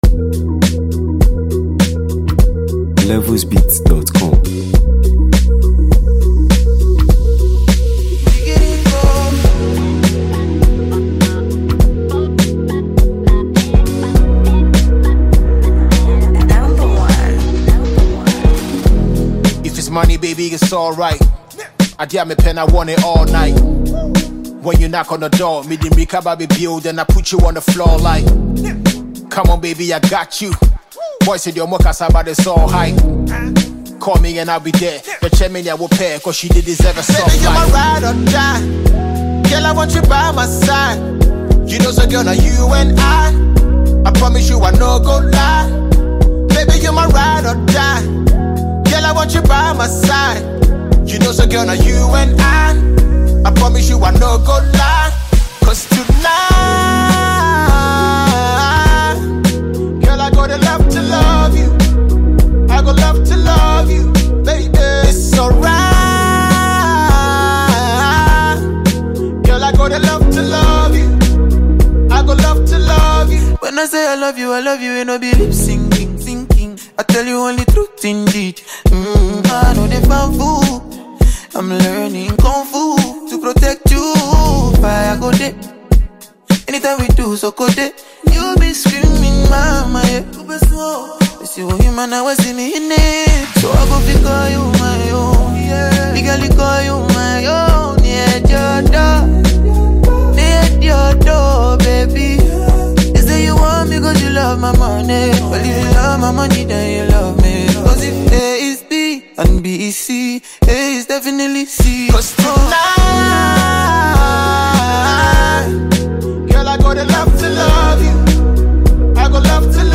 Ghana Music 2025 2:40
Multi-award-winning Ghanaian rap icon and songwriter
a fresh sound that blends rap and Afrobeats seamlessly
a fast-rising and sensational Afrobeats singer-songwriter
smooth vocals and catchy melodies